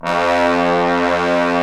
Index of /90_sSampleCDs/Roland L-CD702/VOL-2/BRS_Bs.Trombones/BRS_Bs.Bone Sect